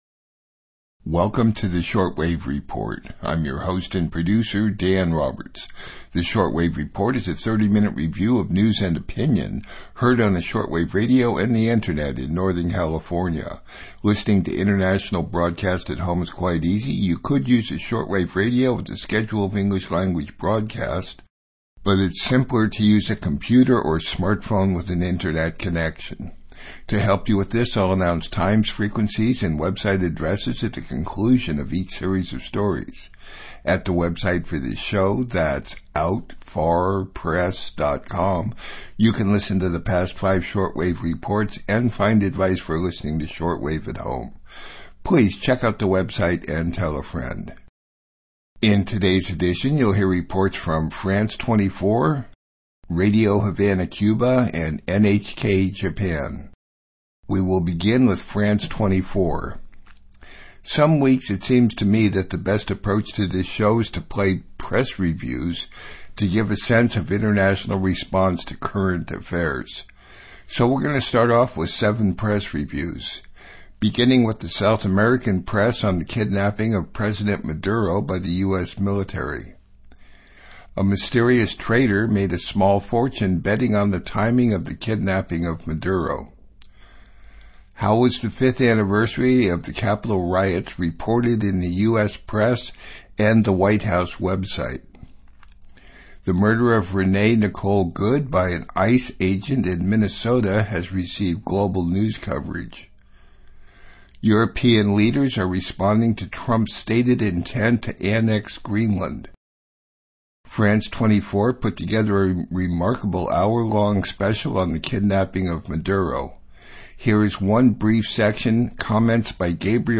International News, News Program